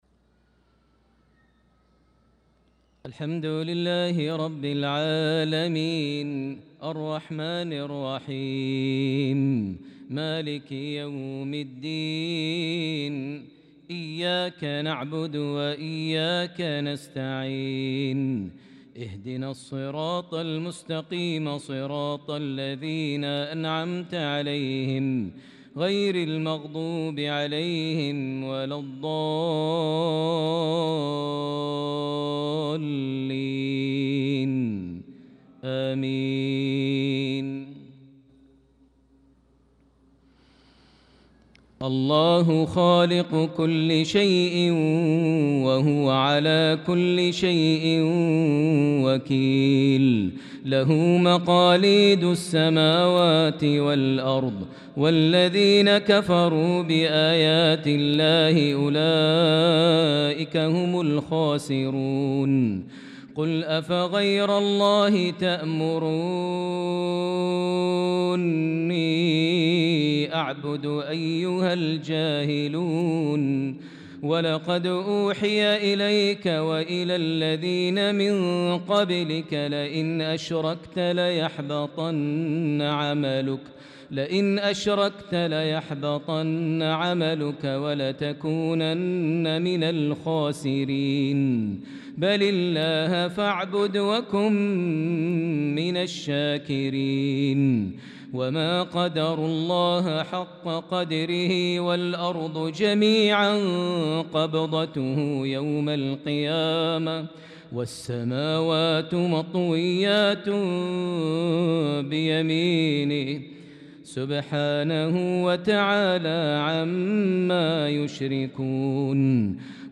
صلاة العشاء للقارئ ماهر المعيقلي 9 ذو القعدة 1445 هـ
تِلَاوَات الْحَرَمَيْن .